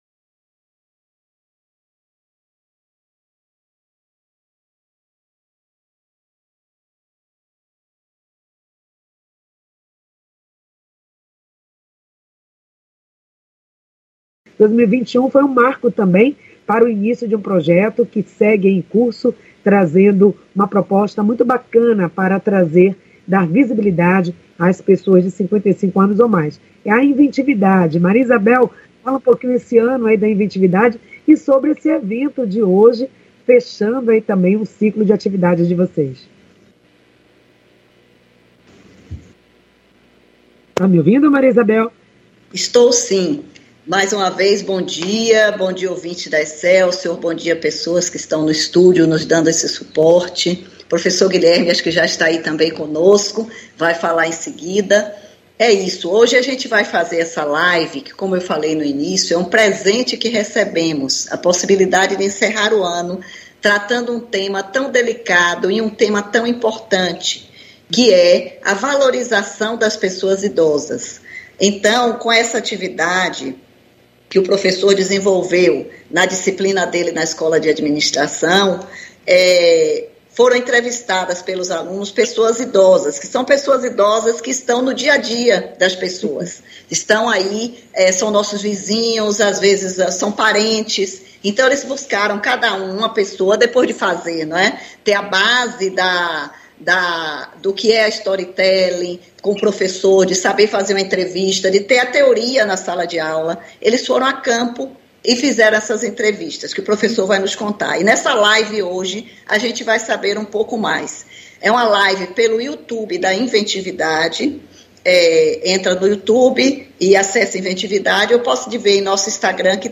O programa Excelsior Saúde, acontece das 9h às 10h, ao vivo, com transmissão pela Rádio Excelsior AM 840.